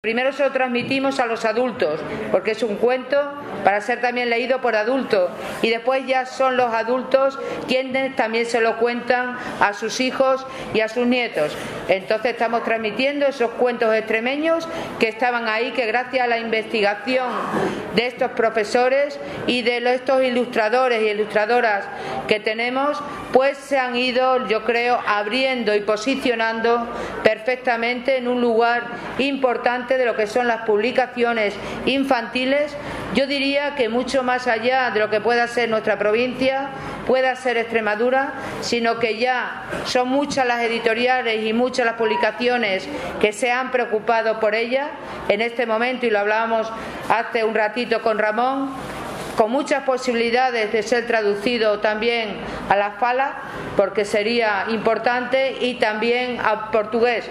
CORTES DE VOZ
24/04/2018, Cáceres.- La presidenta de la Diputación de Cáceres, Rosario Cordero, acompañada del diputado de Cultura, Juventud y Deportes, Álvaro Sánchez Cotrina, ha querido este martes estar en la Feria del Libro de Cáceres para la presentación de tres nuevos volúmenes bilingües español/inglés de una de las colecciones considerada “estrella” de la Institución Cultural El Brocense, como es la colección “El Pico de la Cigüeña”.